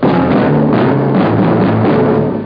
7-note segue